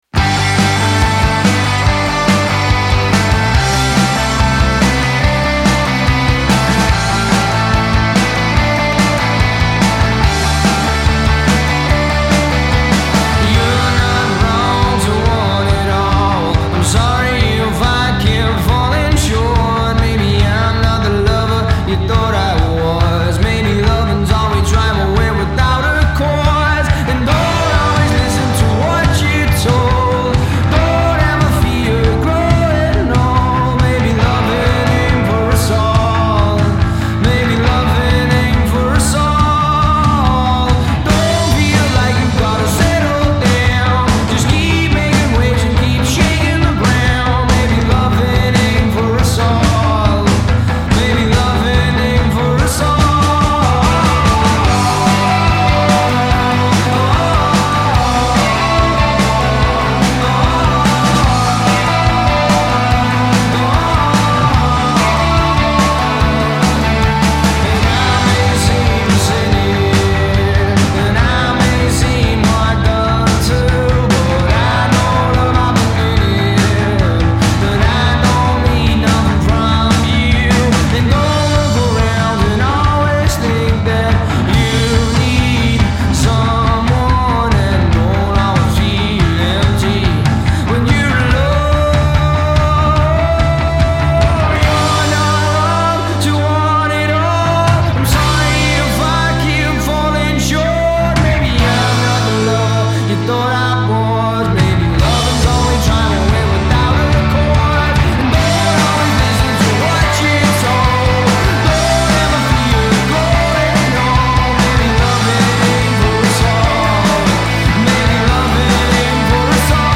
Indie rockers